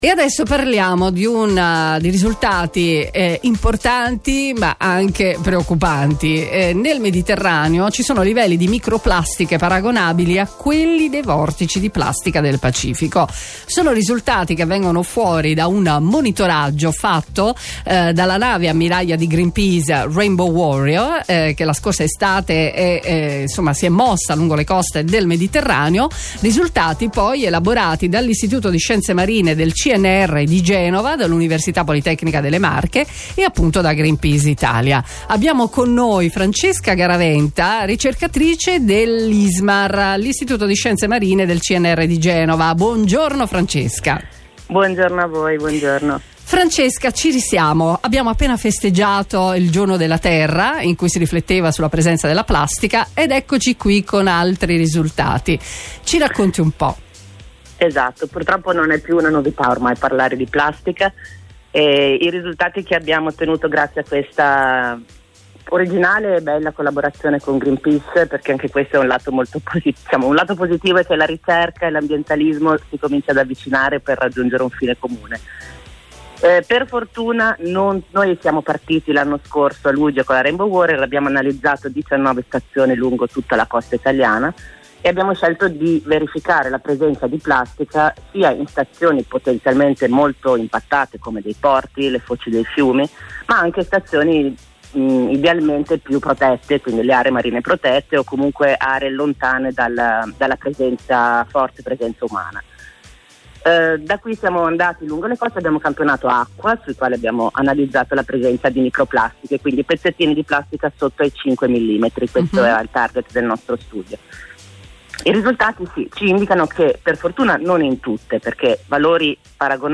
intervista via Radio Kiss Kiss del 24 Aprile 2018